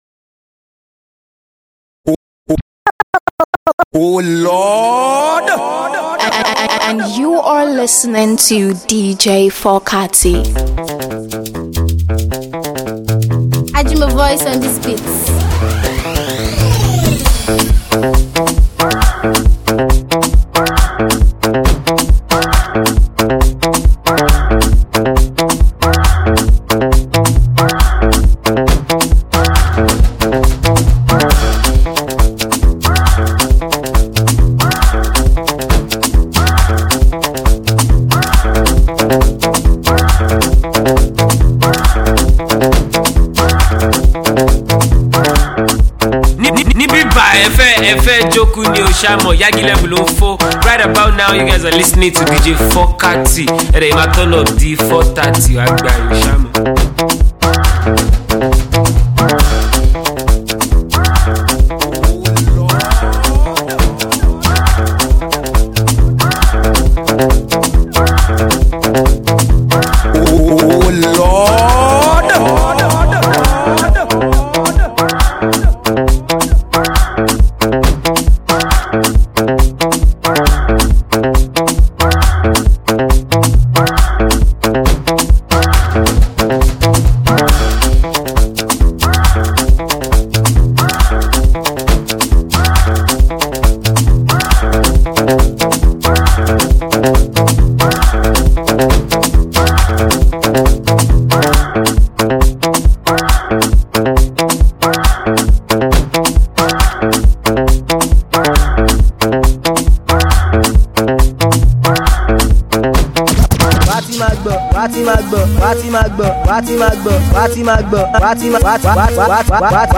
trending street bangers, jam and anthems